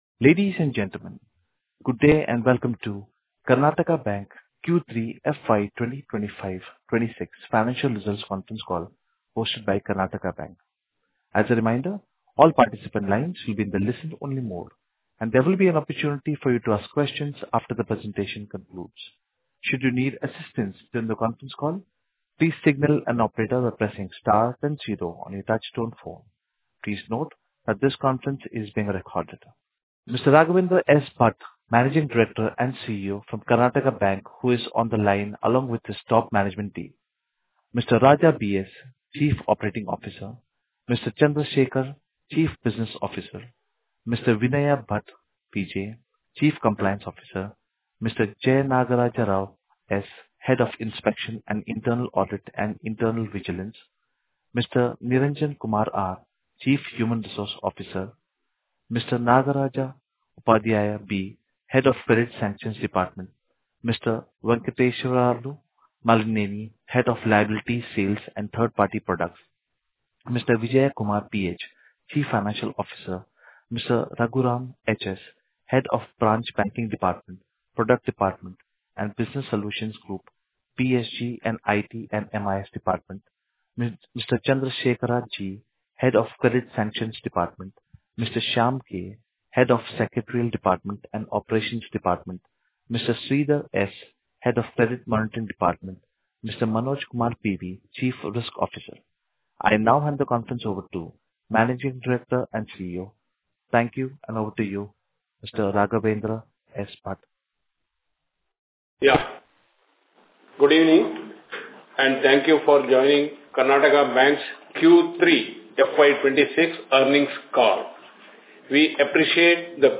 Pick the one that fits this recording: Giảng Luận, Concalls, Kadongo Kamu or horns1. Concalls